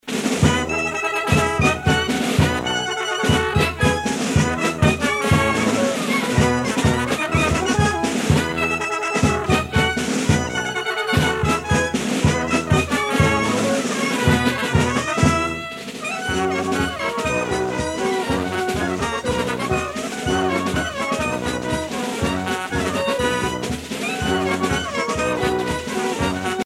circonstance : carnaval, mardi-gras